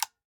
ak_firemode_switch.ogg